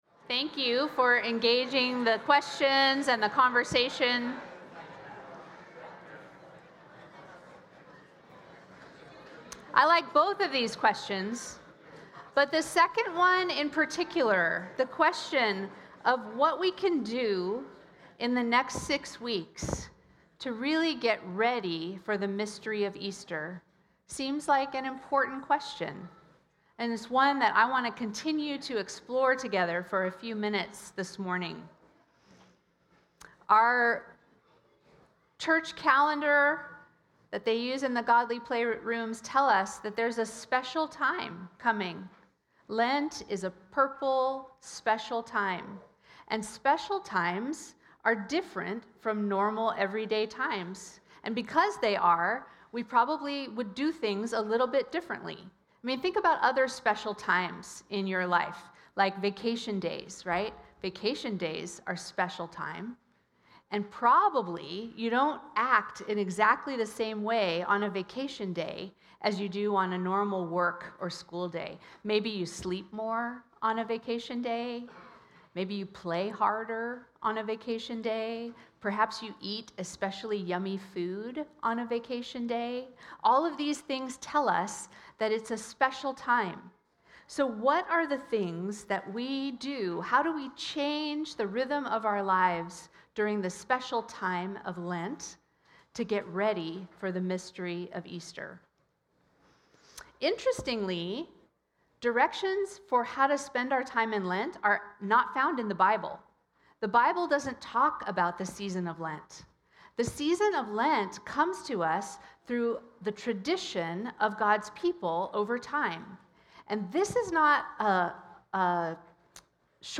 The River Church Community Sermons